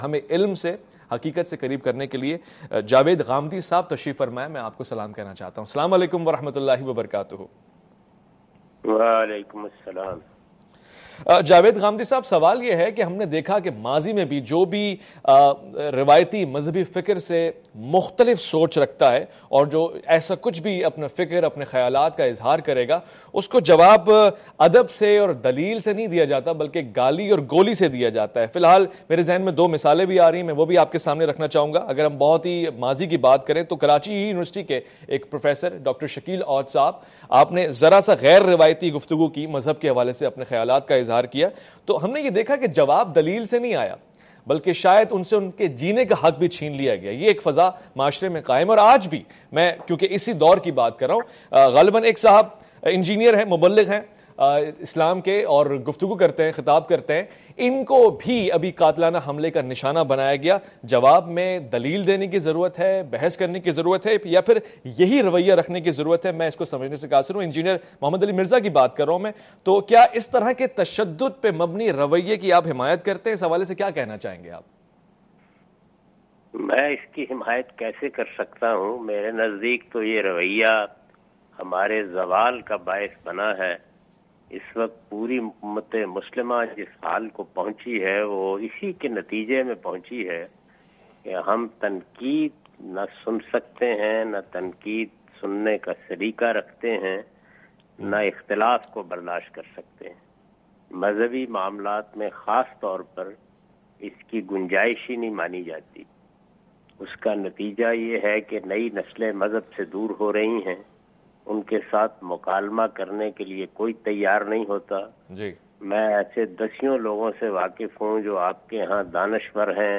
Category: TV Programs / Questions_Answers /
In this Video Mr. Javed Ahmad Ghamidi answers some important question about "Culture of tolerance in religious debates".